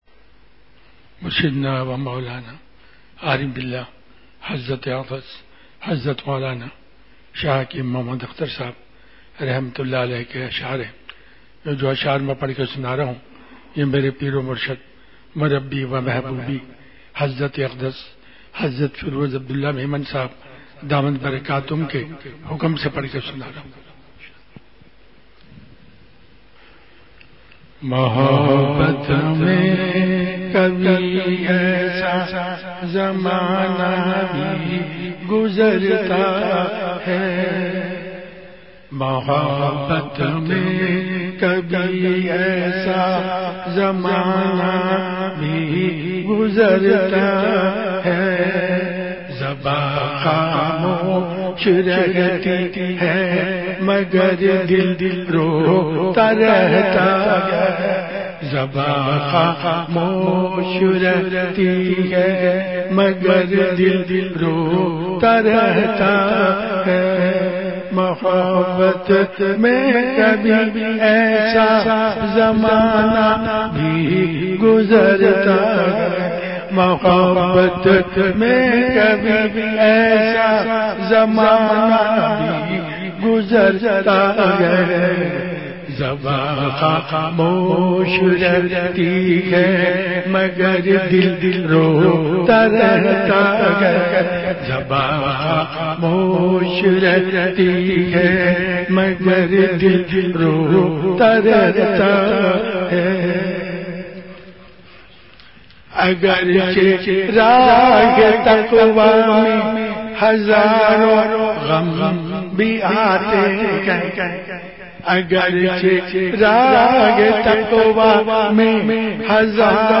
*مقام:مسجد اختر نزد سندھ بلوچ سوسائٹی گلستانِ جوہر کراچی*
*15:42) بیان کے آغاز میں اشعار کی مجلس ہوئی۔۔۔*